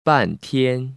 [bàntiān] 빤티엔